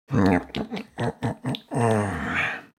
دانلود صدای شب 9 از ساعد نیوز با لینک مستقیم و کیفیت بالا
جلوه های صوتی